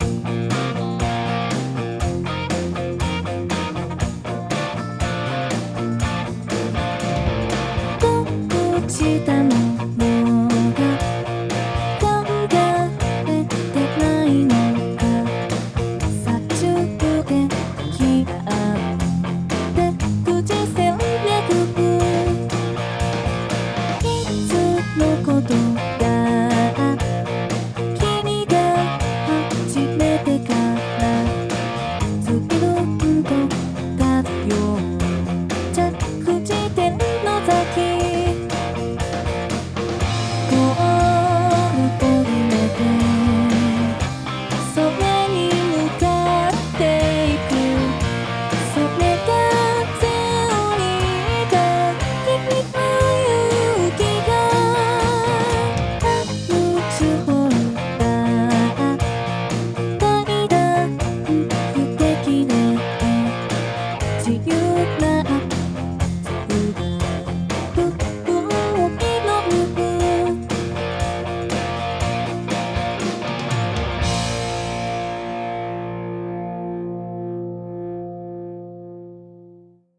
No.01216 [歌]
※Band-in-a-Boxによる自動作曲